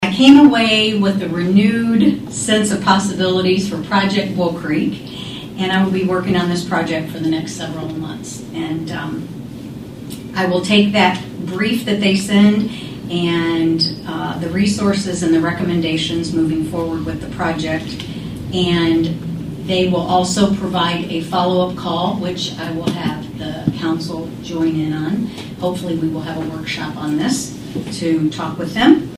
Mayor Garrett, speaking at Wednesday’s City Council meeting, says this was an amazing experience and a good opportunity for the city of Atlantic. She says each of the six mayors shared their project, including Atlantic’s, which is improving Bull Creek.